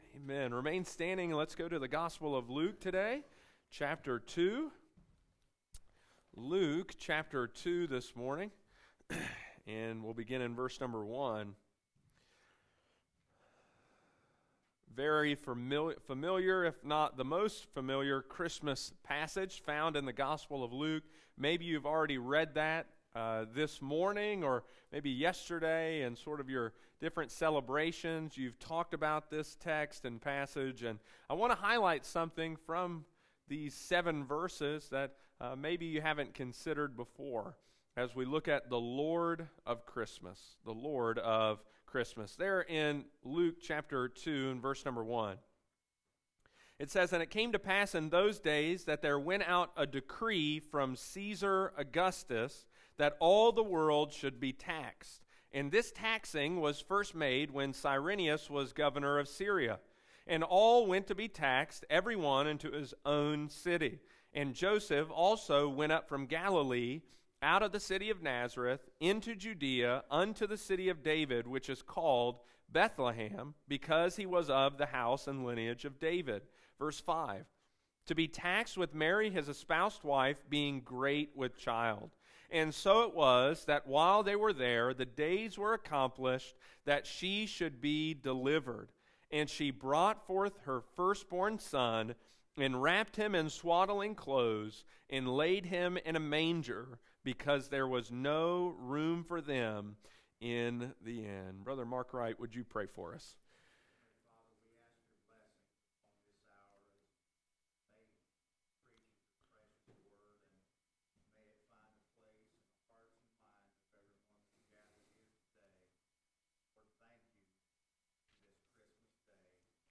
preaches on Christmas Day about the true Lord of Christmas!